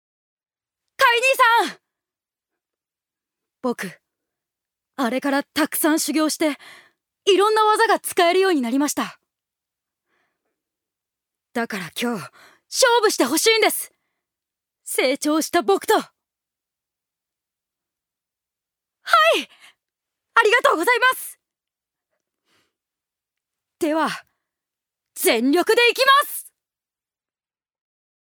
女性タレント
セリフ５